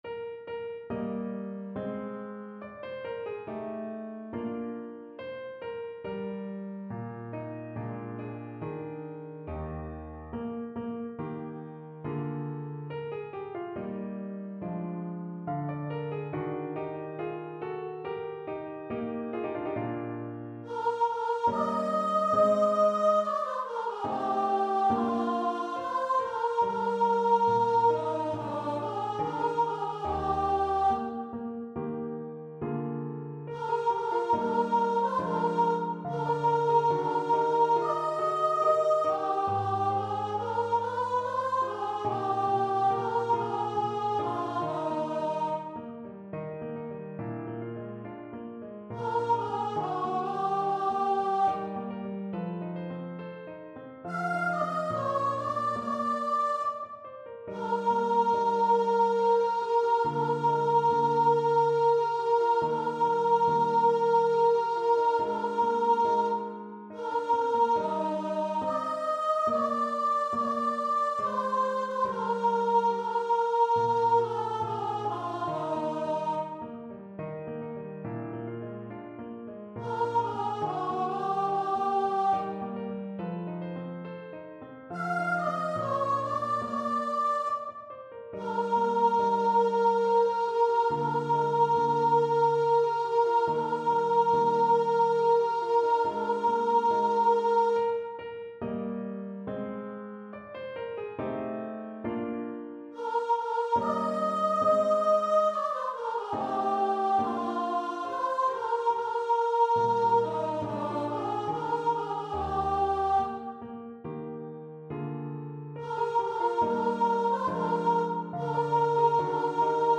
Choir version
Free Sheet music for Choir (SATB)
Classical (View more Classical Choir Music)